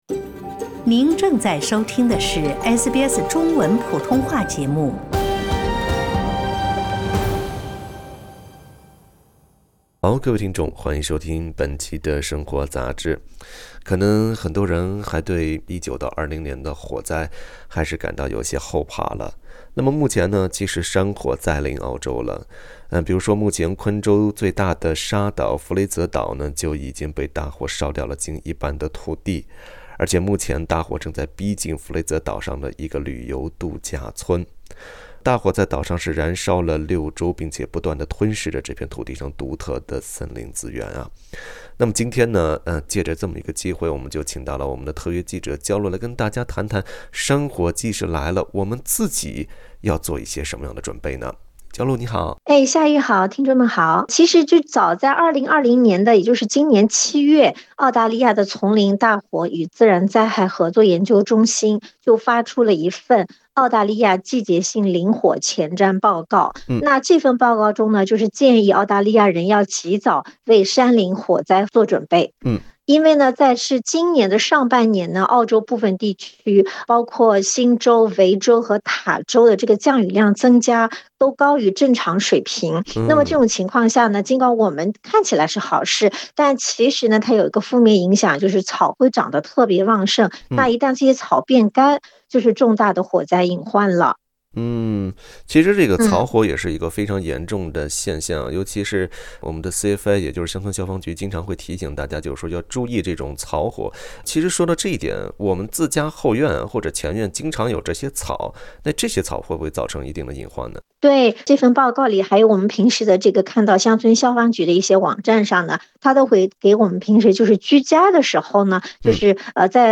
山火季再临，如何防患于未然？乡村消防局提醒居民，预防山火可以从自己的前后院着手。（点击音频，收听完整采访）